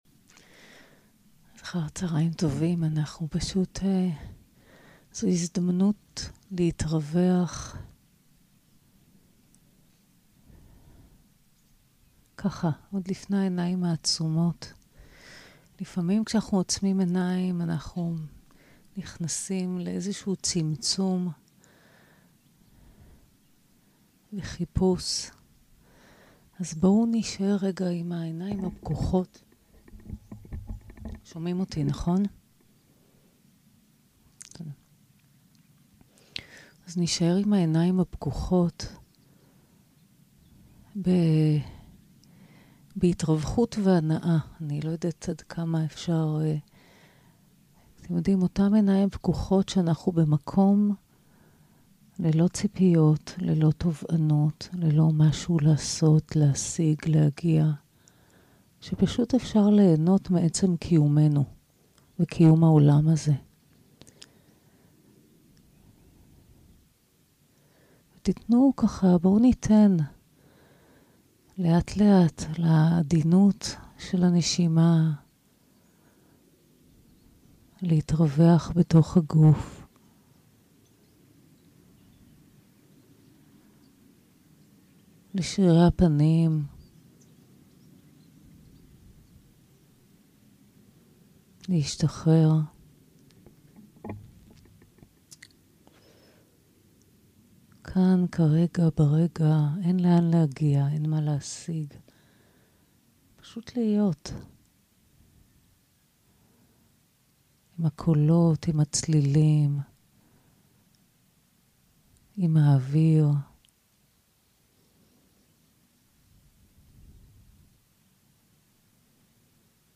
יום 2 - הקלטה 3 - צהרים - מדיטציה מונחית - להיות חלק מהטבע - תרגול עם ארבעת האלמנטים
יום 2 - הקלטה 3 - צהרים - מדיטציה מונחית - להיות חלק מהטבע - תרגול עם ארבעת האלמנטים 0:00 0:00 סוג ההקלטה: Dharma type: Guided meditation שפת ההקלטה: Dharma talk language: Hebrew